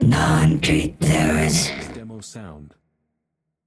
“No entry, there is” Clamor Sound Effect
Can also be used as a car sound and works as a Tesla LockChime sound for the Boombox.